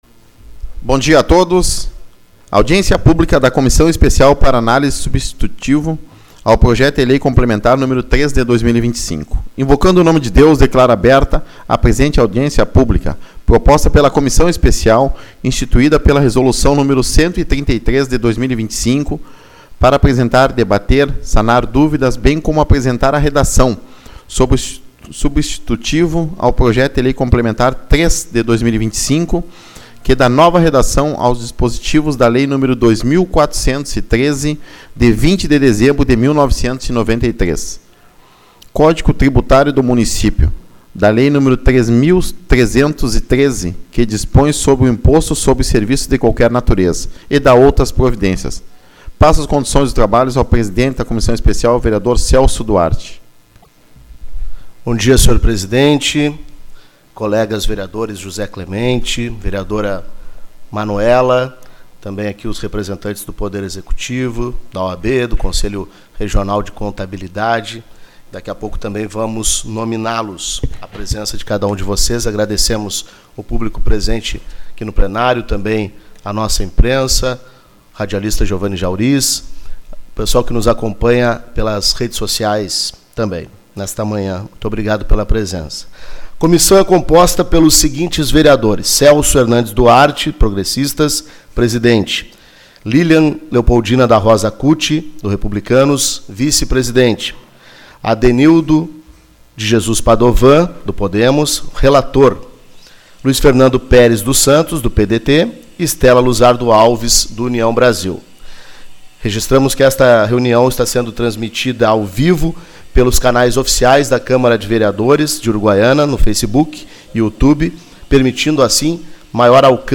03/12 - Audiência Pública-PL 03/2025